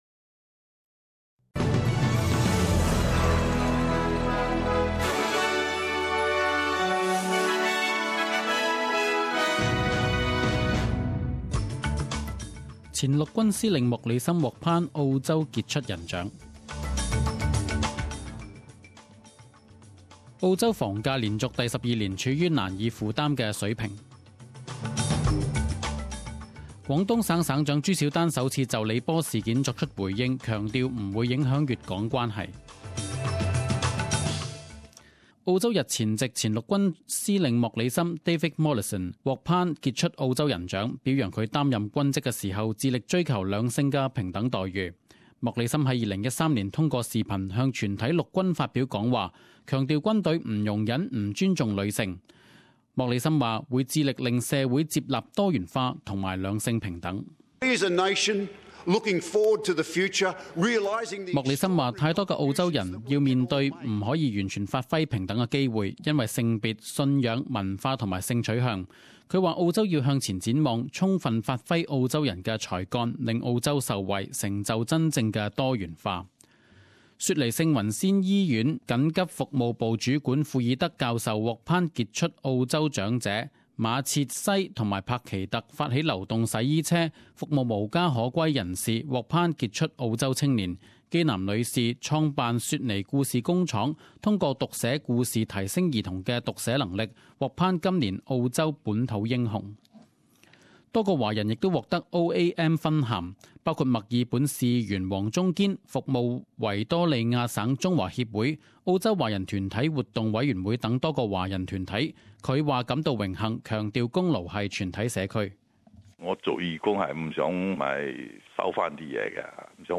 十点钟新闻报导 （一月二十六日）